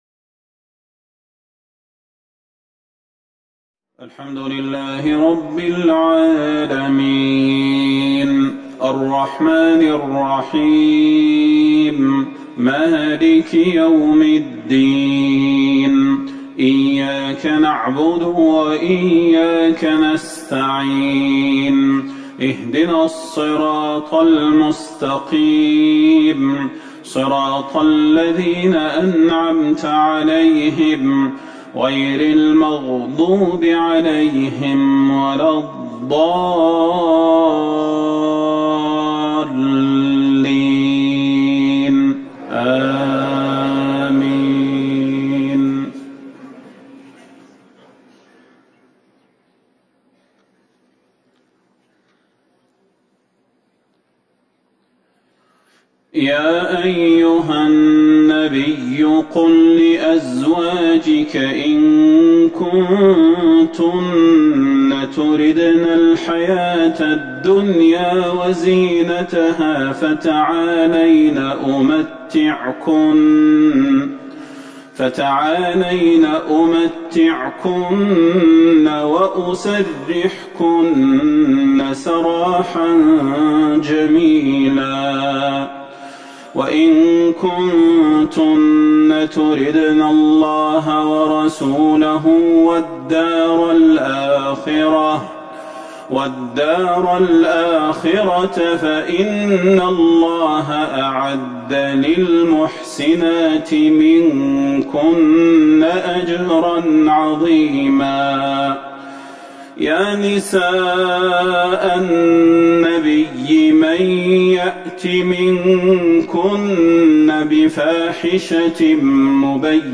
صلاة العشاء ٨ جمادي الاخره ١٤٤١هـ سورة الاحزاب Isha prayer 2-2-2020 from Surah Al-Ahzab > 1441 🕌 > الفروض - تلاوات الحرمين